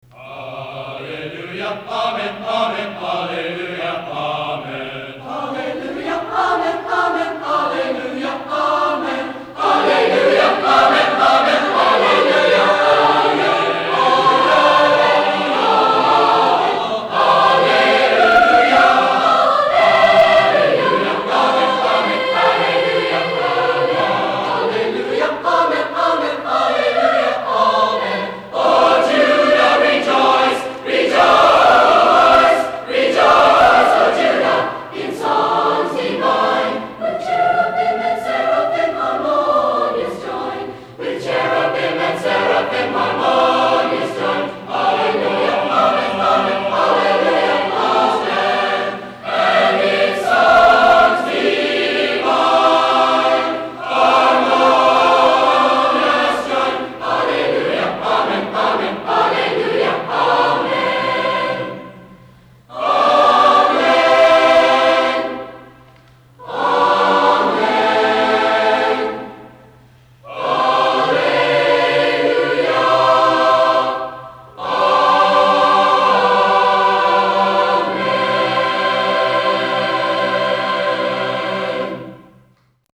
Spring Concert
Clay High Gym